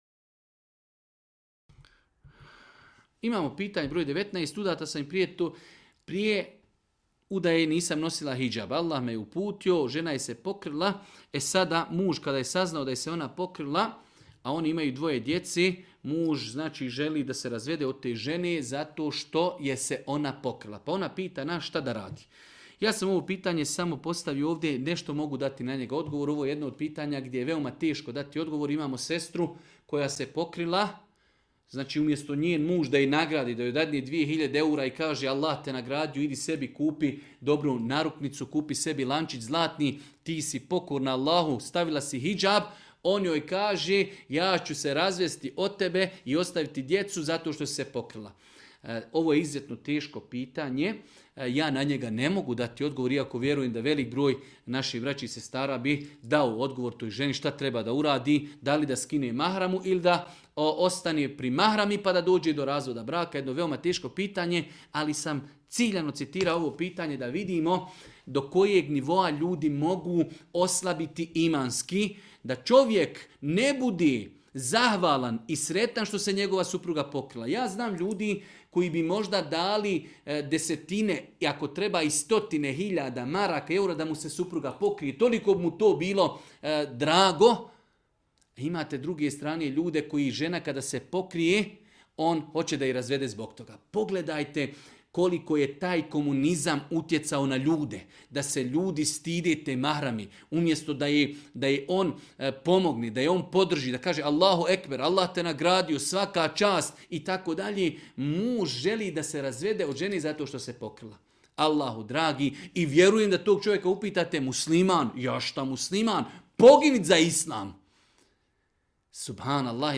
u video predavanju ispod